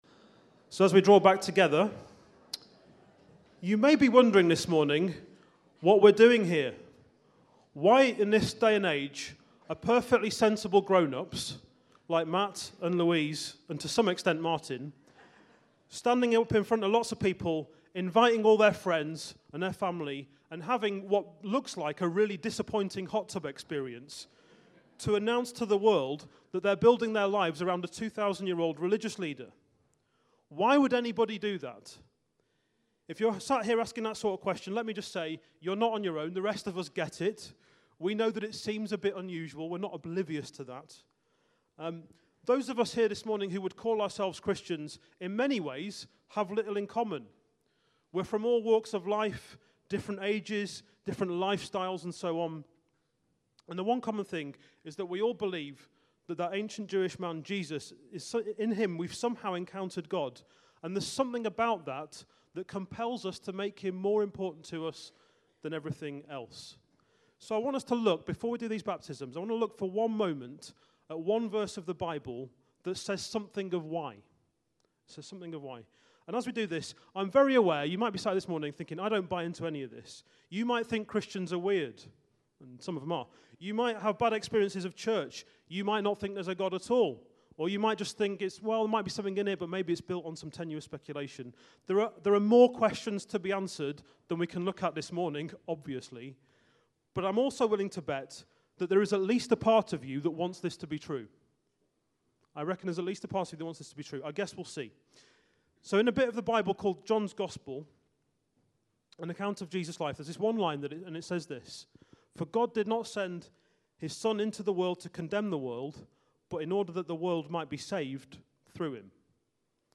Baptism Sunday - 10th February 2019